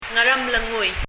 Ngaremlengui　という綴りをどうローマ字読みしても「アルモノグイ」にはならないのですが、ひょっとするとそう聞こえるかも、と３回発音してもらいましたが、「アルモノグイ」とは聞こえませんでした。
発音
もし、ムリヤリ　カナ表記するのでしたら、「(ガ）アラムレ（グ）ゥイ」あたりかな？